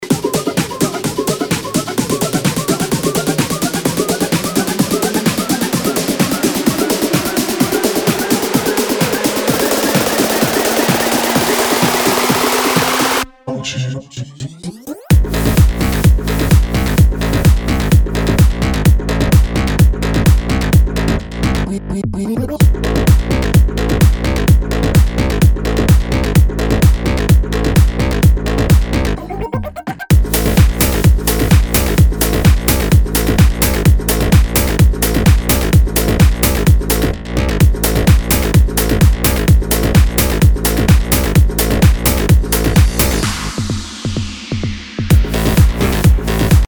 DJ and producer of tech house & house music
His style is unique and electrifying.